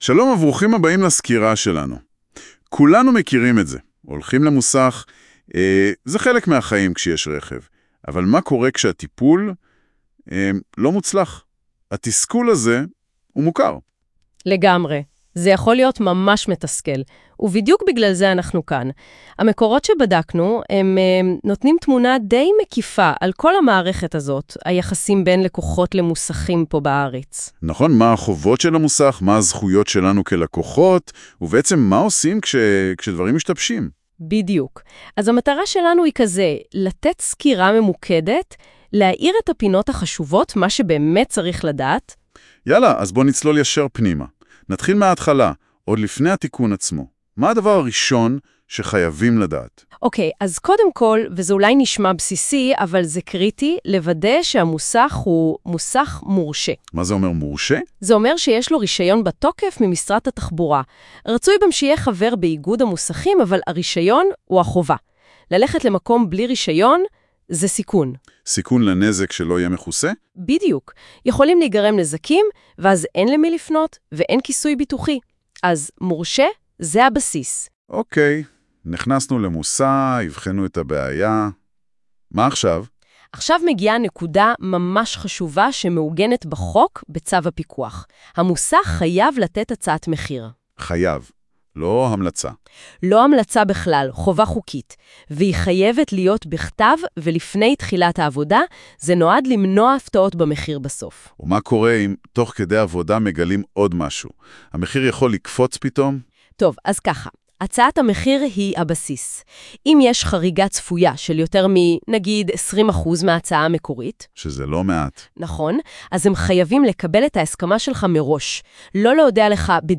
מצורף הסבר קולי קצר להעשרה בנושא התנהלות משפטית מול מוסך (בעריכת המשרד):